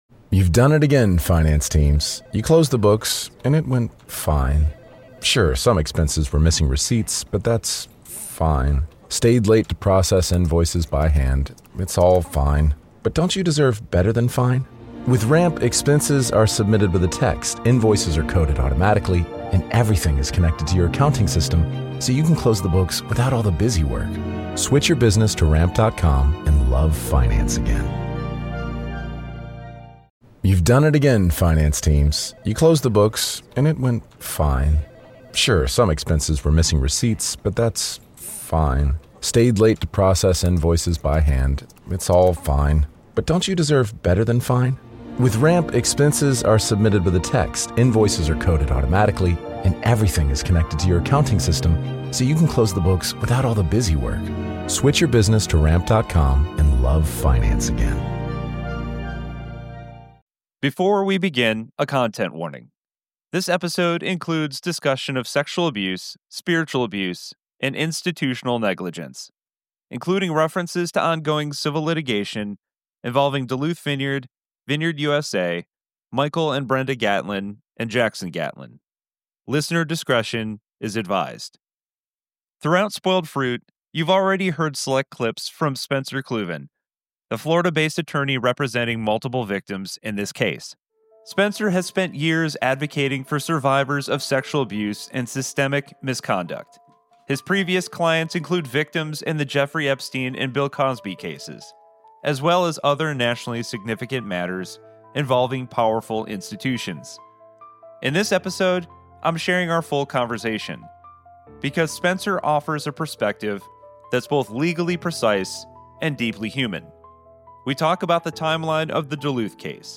⚠ Content Warning: This episode includes discussion of sexual abuse, spiritual abuse, emotional trauma , and ongoing legal proceedings involving high-profile defendants. It may also contain strong language.